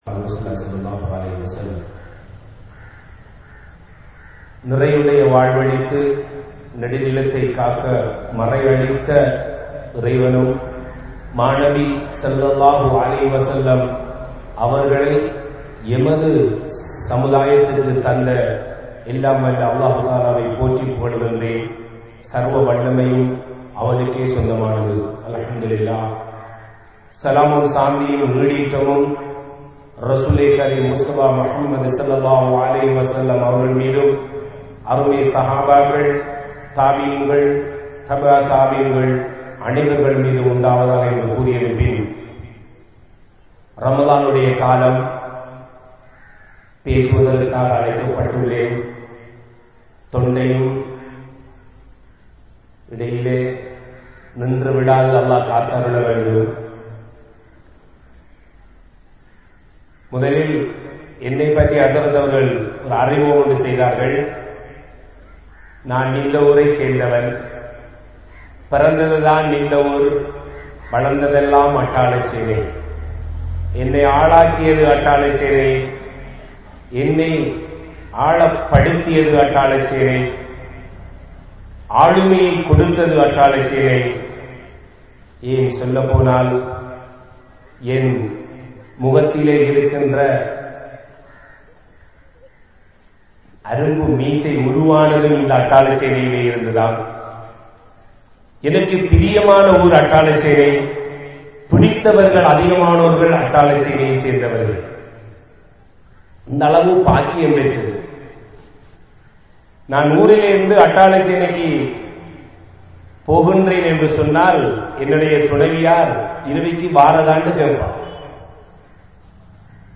Eemaan Kondavarhal(ஈமான் கொண்டவர்கள்) | Audio Bayans | All Ceylon Muslim Youth Community | Addalaichenai